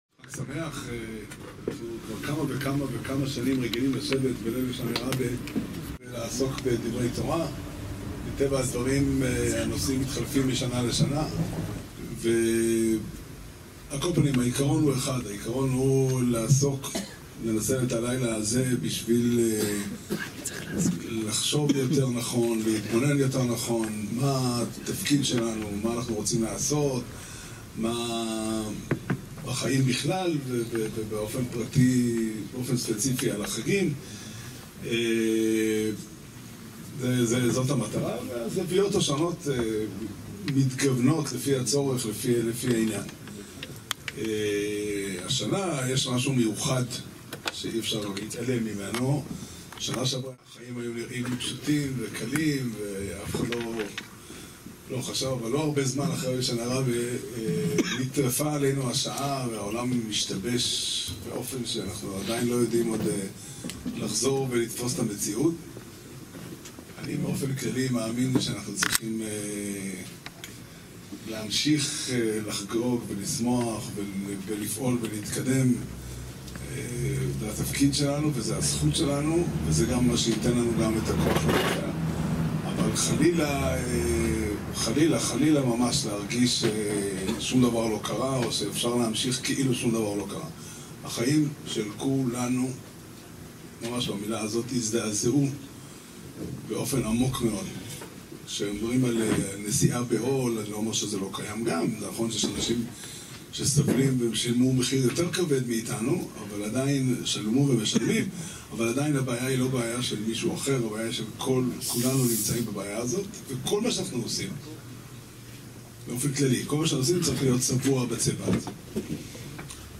השיעור נמסר במסגרת הלימוד השנתי בליל הו"ר תשפ"ה בסוכה בהר נוף